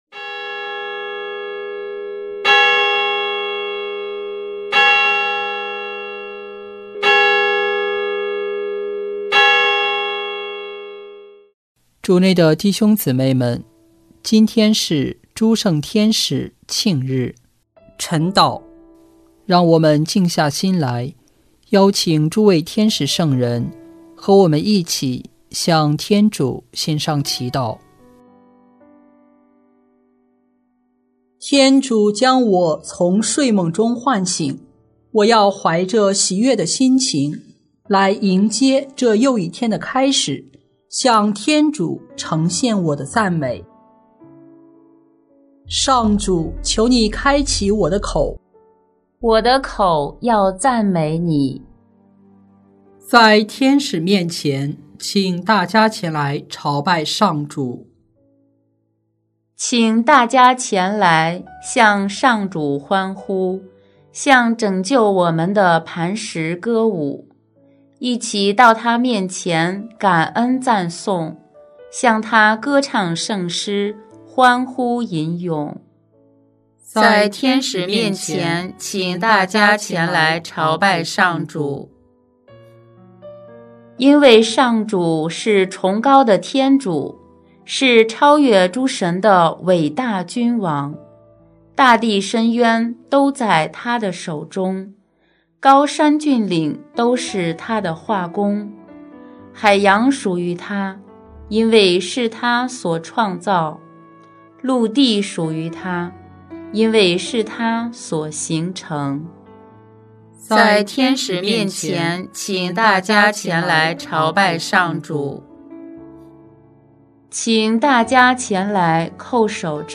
【每日礼赞】|9月29日诸圣天使庆日晨祷